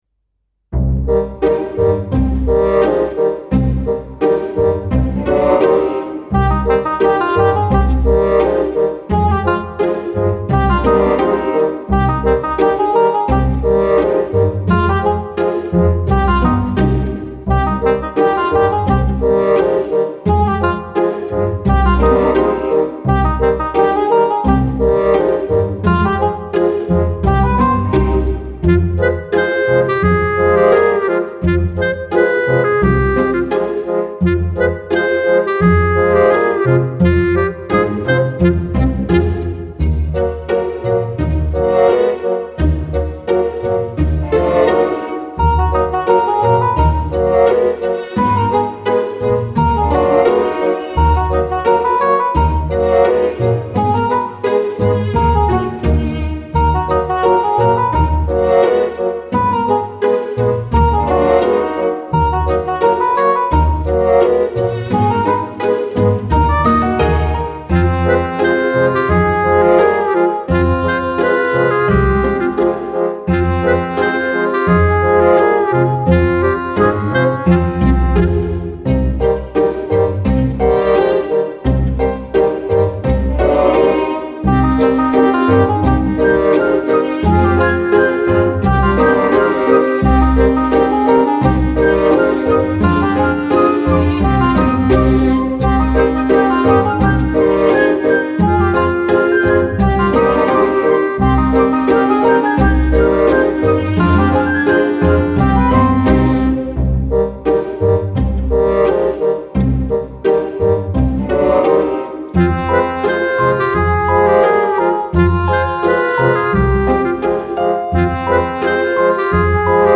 suspense - pizzicati - pizzicato - violons - clarinette